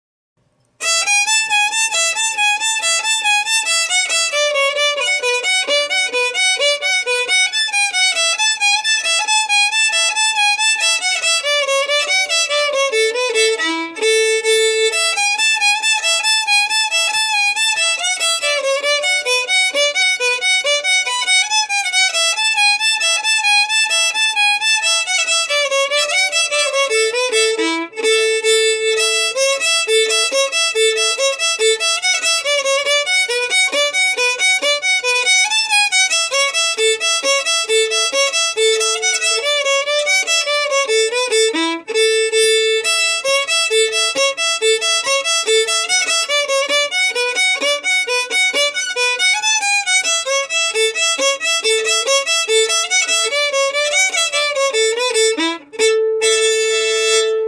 Key: A
Form: Reel
M: 4/4
Region: Scotland